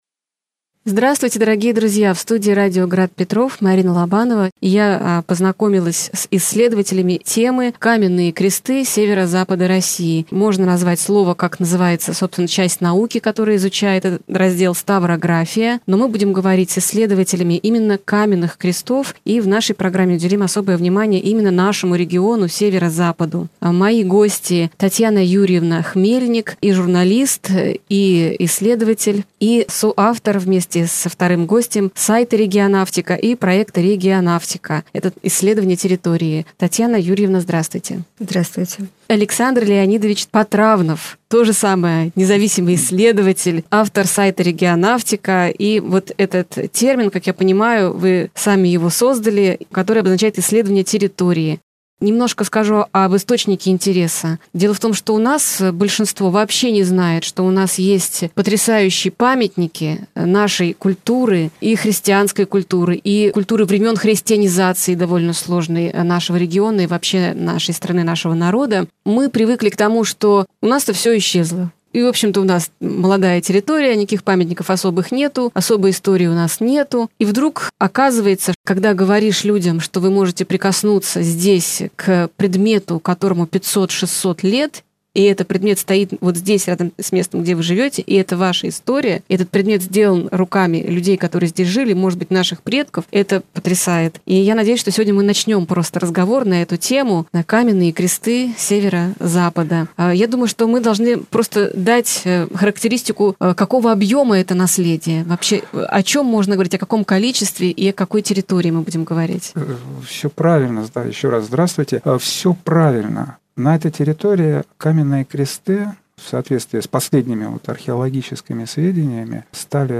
исследователи каменных крестов Северо-Запада России.